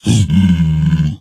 Minecraft Version Minecraft Version snapshot Latest Release | Latest Snapshot snapshot / assets / minecraft / sounds / mob / zombified_piglin / zpigangry1.ogg Compare With Compare With Latest Release | Latest Snapshot
zpigangry1.ogg